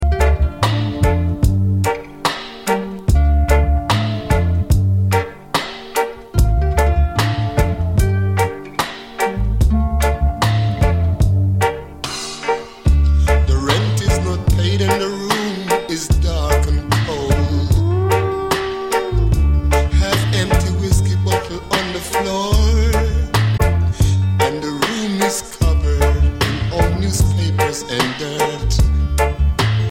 Reggae Ska Dancehall Roots Vinyl Schallplatten Records ...